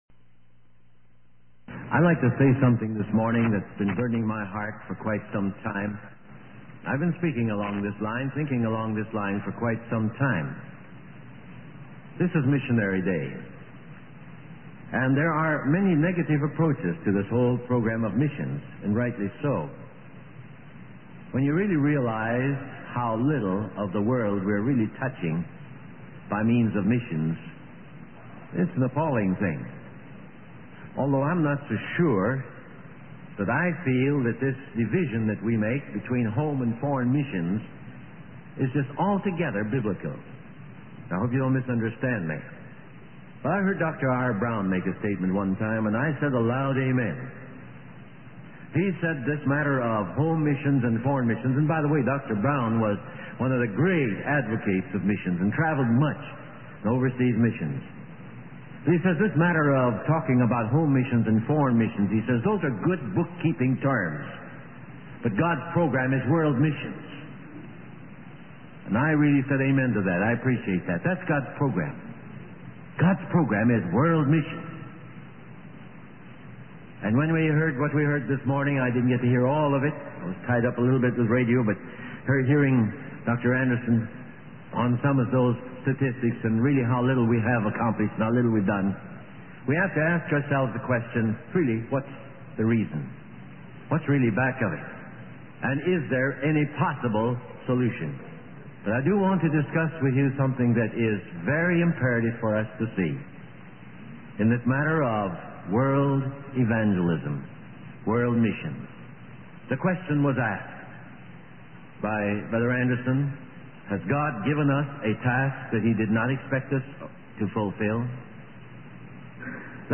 In this sermon, the speaker emphasizes the importance of following the course that God has laid out for each individual.